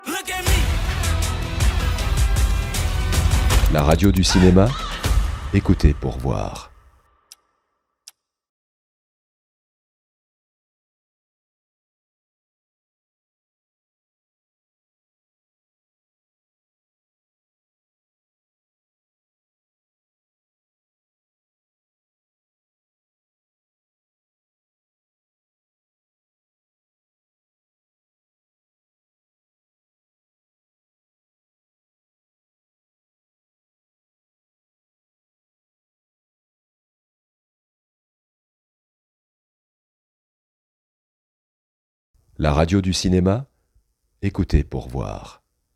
Repliques et musiques de films et series 24/24